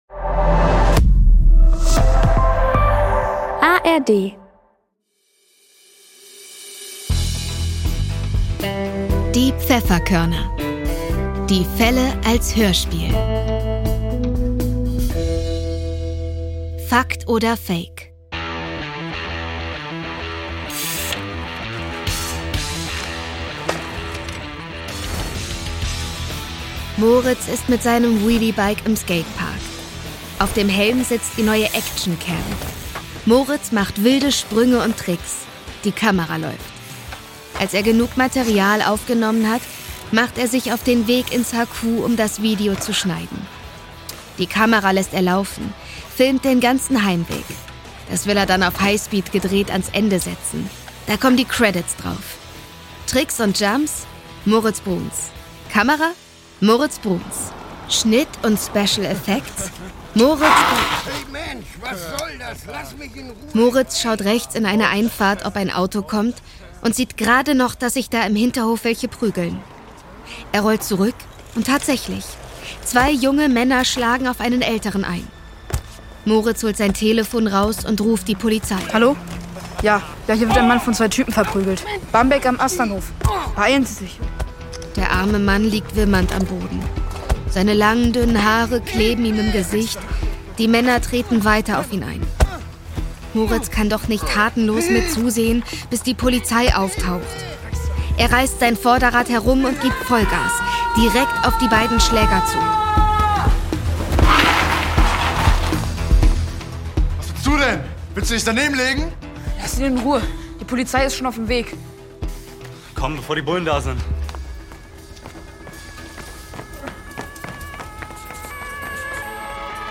Folge 17 - Fakt oder Fake ~ Die Pfefferkörner - Die Fälle als Hörspiel Podcast